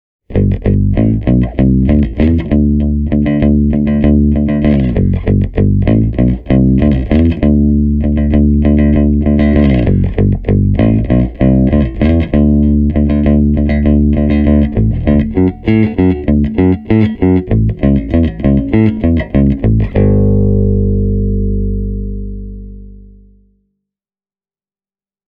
Bassorekisteri on lämmin, keskialue siisti ja yläkerta silkkisen pehmeä.
Seuraavat esimerkit on äänitetty käyttämällä samanaikaisesti sekä kondensaattorimikrofonia kaiuttimen edessä että kombon suoraa XLR-lähtöä:
1989 Höfner 500/1